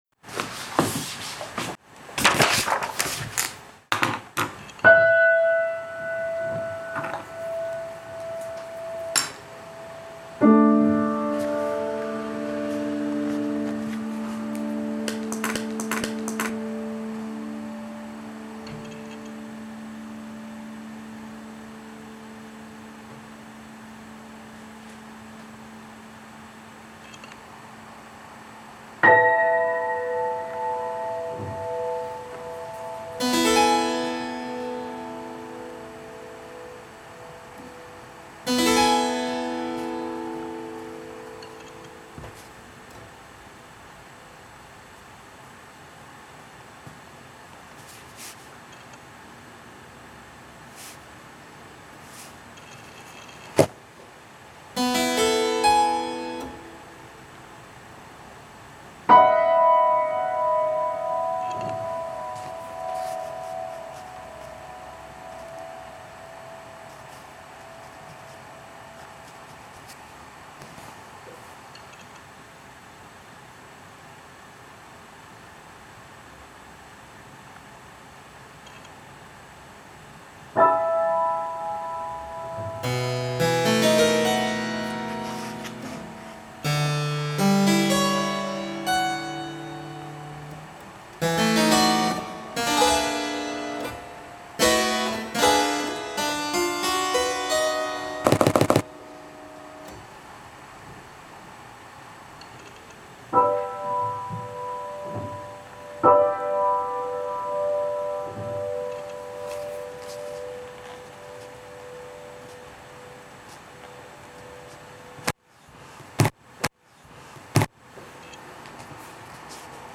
Zuspielung
Cembalo